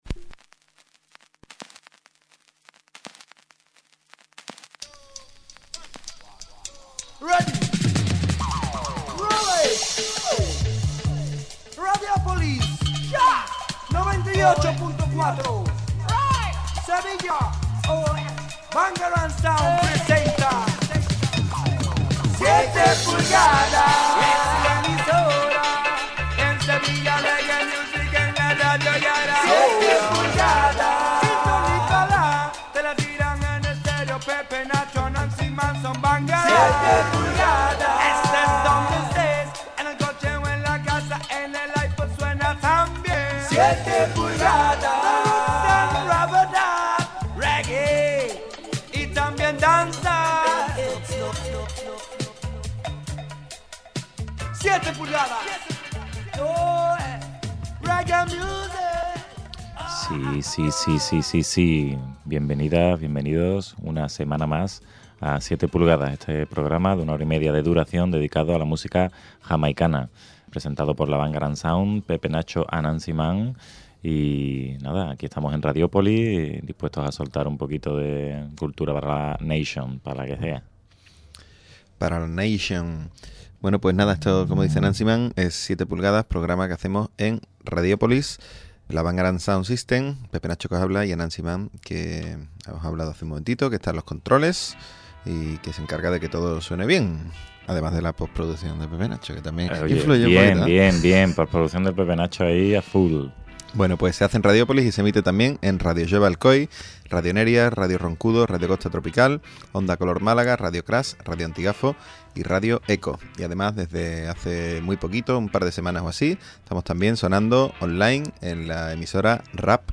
Presentado y dirigido por la Bangarang Sound.